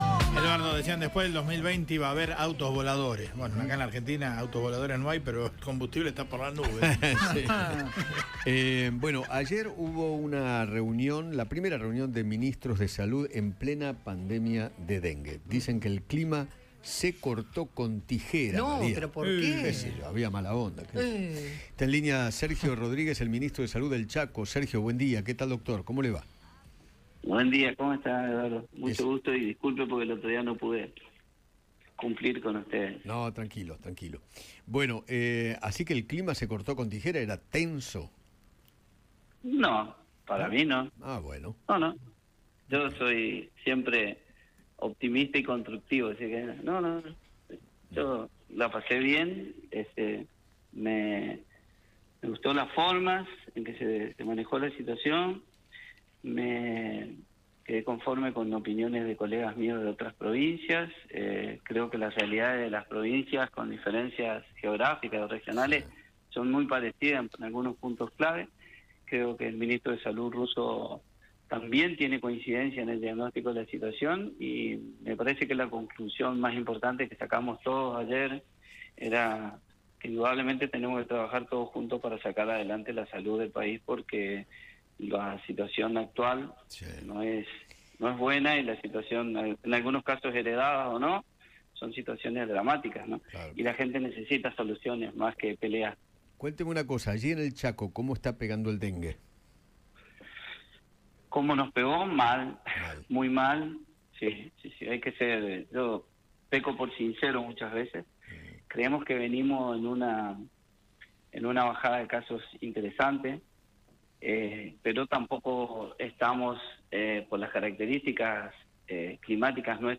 Sergio Rodríguez, ministro de Salud de Chaco, conversó con Eduardo Feinmann sobre el brote histórico de dengue y se refirió a la reunión que mantuvieron todos los ministros de salud del país.